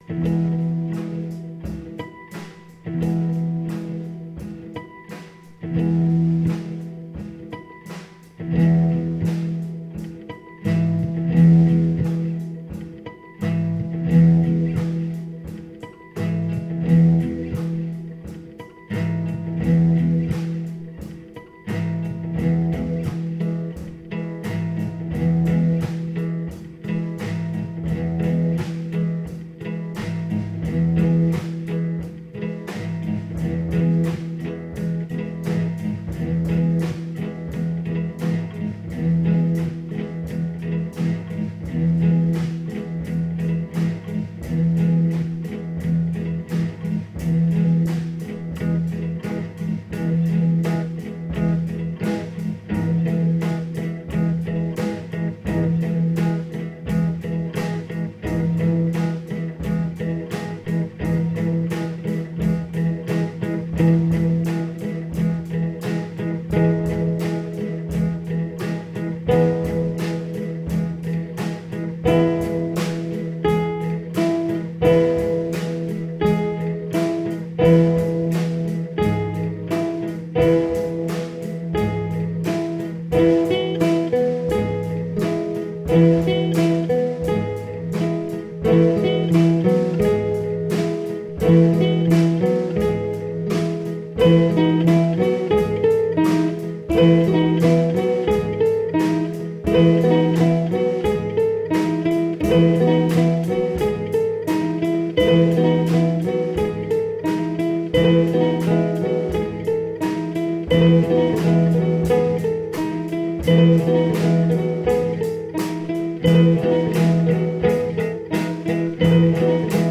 builds up from little
This one has a jarring part in the middle.